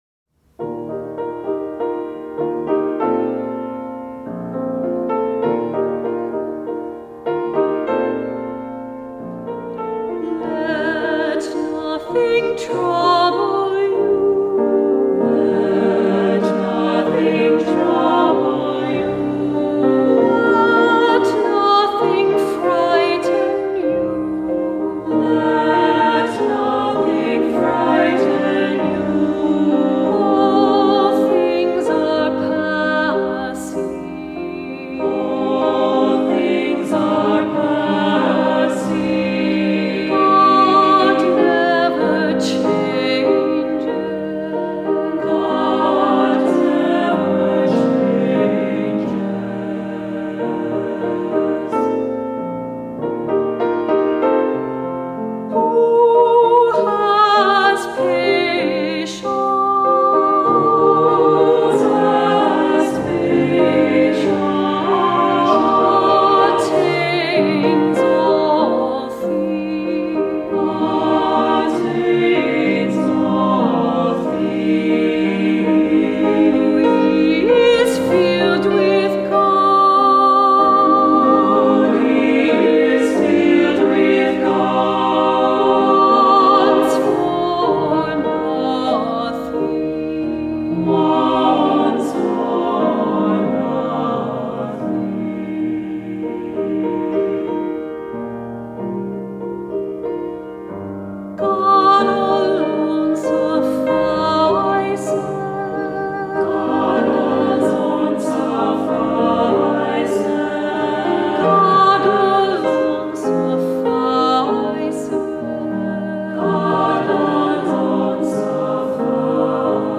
Voicing: SAB, cantor, assembly